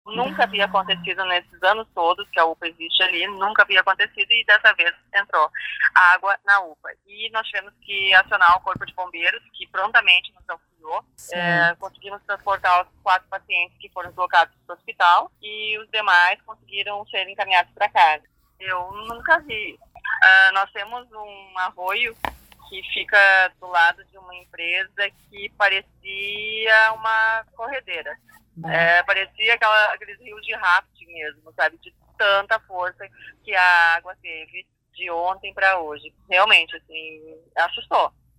Sete prefeitos ouvidos pela reportagem da Guaíba relatam os dramas enfrentados pelas diferentes regiões do Estado
No início do ciclone, ainda durante a noite desta quinta-feira, 12 pacientes da UPA do município tiveram de ser remanejados porque o local ficou totalmente alagado, conforme relata a prefeita de Sapiranga, Carina Nath.